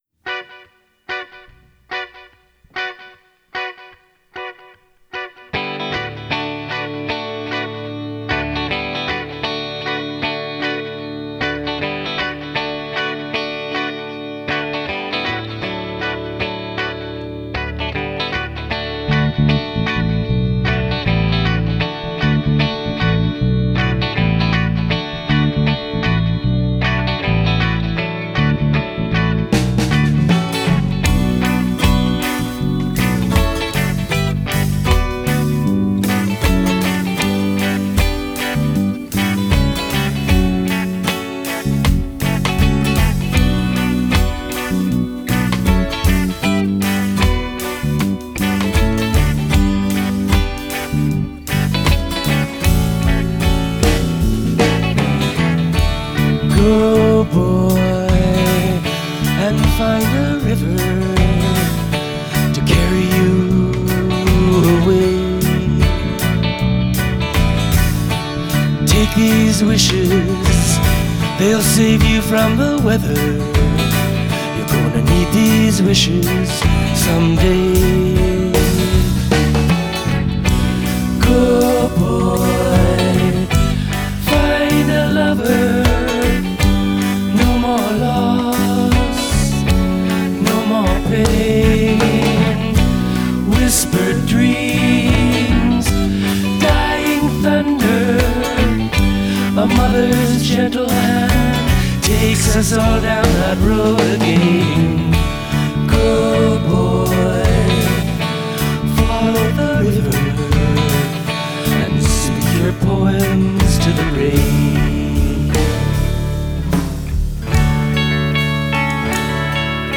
lead guitar/keyboards/vocals
guitar/piano/vocals
bass/vocals
drums/vocals
Additional vocals